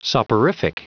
Prononciation du mot soporific en anglais (fichier audio)
Prononciation du mot : soporific